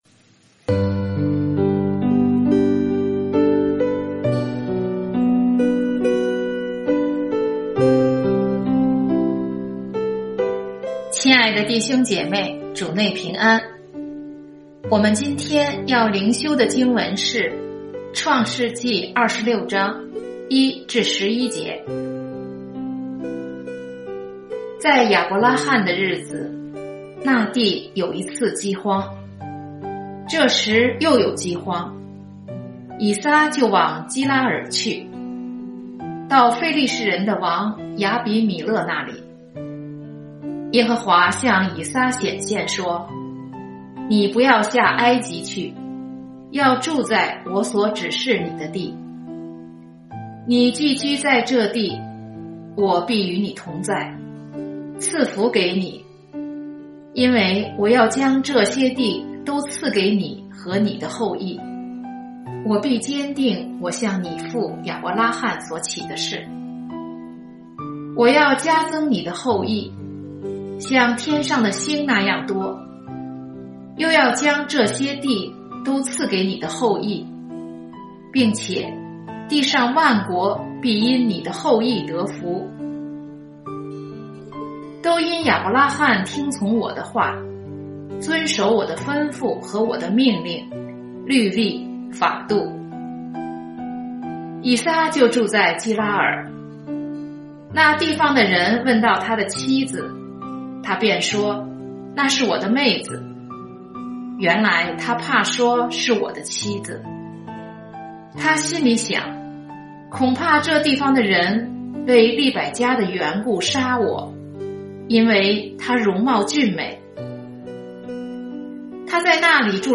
這是把通讀整卷創世記和每天靈修結合起來的一個計劃。每天閱讀一段經文，聆聽牧者的靈修分享，您自己也思考和默想，神藉著今天的經文對我說什麼，並且用禱告來回應當天的經文和信息。